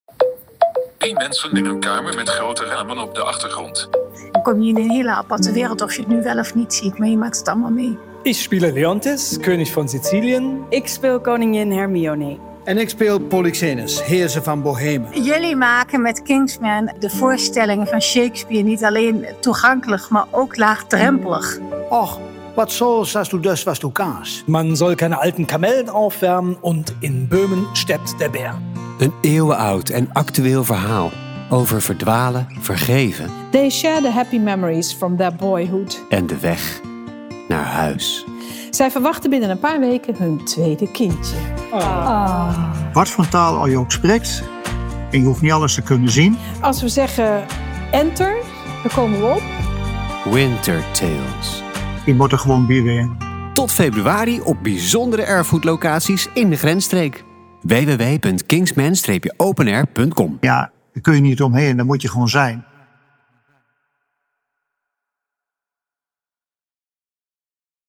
Nieuwsgierig? Beluister hier onze audiotrailer...